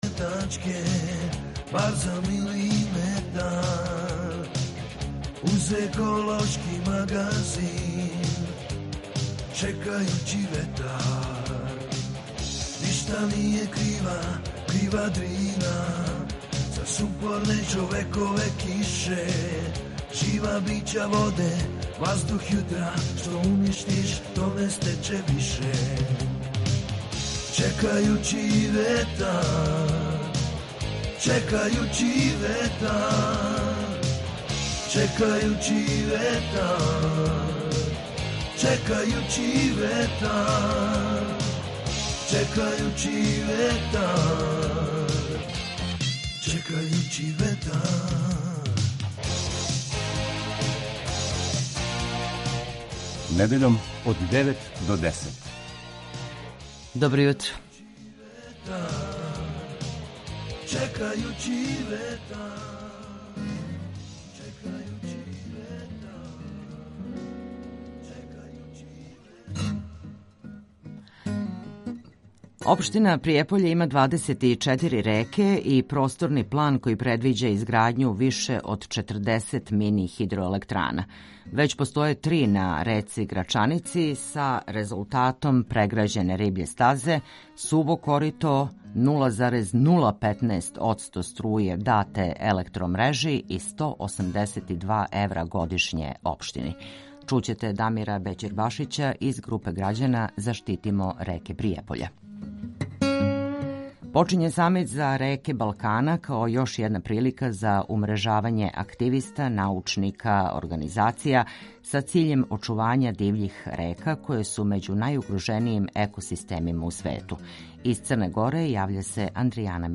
O potrebnoj međudržavnoj saradnji i iskustvima iz Slovenije govori dr Violeta Bulc iz Organizacije Eko civilizacija.
Čekajući vetar - ekološki magazin Radio Beograda 2 koji se bavi odnosom čoveka i životne sredine, čoveka i prirode.